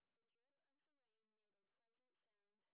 sp12_street_snr20.wav